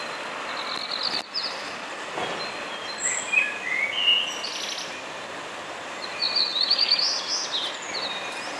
Hybride Haus- x Gartenrotschwanz
Freising FS, 11.06.2012 9 s Singendes Männchen.